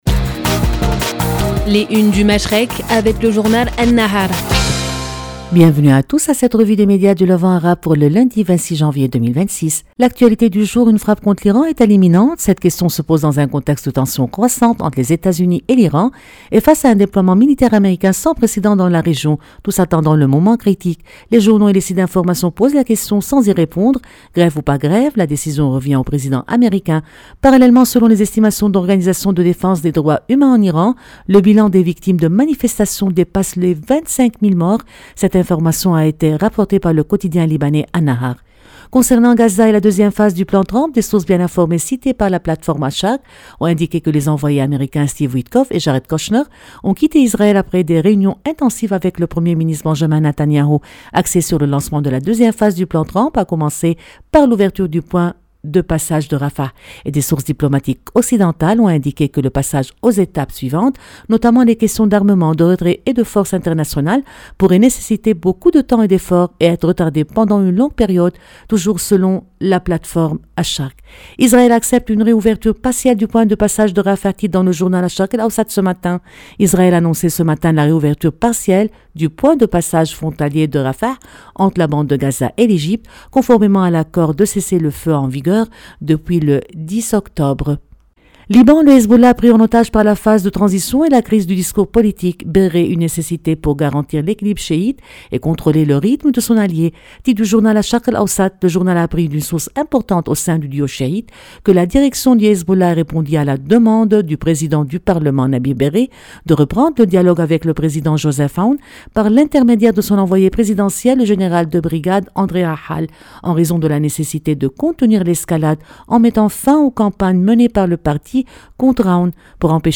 Revue de presse des médias du Moyen-Orient